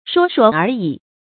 說說而已 注音： ㄕㄨㄛ ㄕㄨㄛ ㄦˊ ㄧˇ 讀音讀法： 意思解釋： 非實質性的事物，空談，閑談 出處典故： 清·無名氏《說呼全傳》第二回：「但是僚友必要恥笑于我，只好 說說而已 的了。」